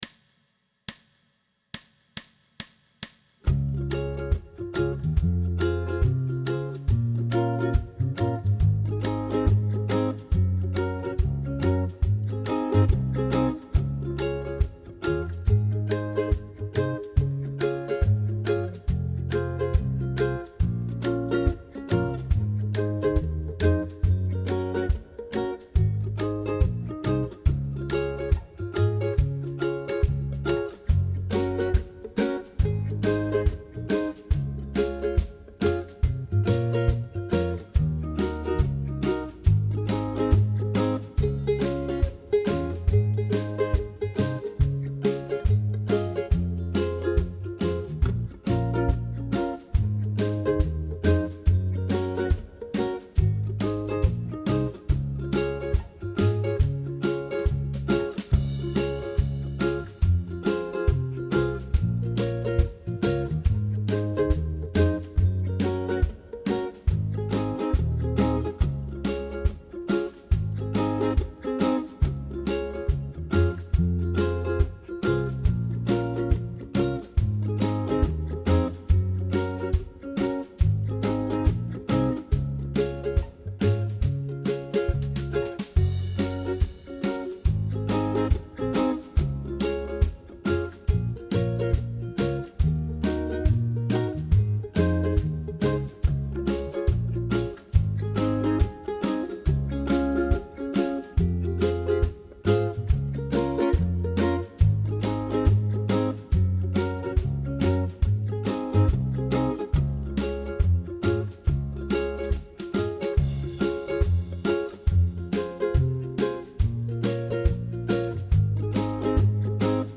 Pop style jam track with Ukulele Rhythm
Tempo: 140BPM
Key of Db
ukulele-pop-140-db.mp3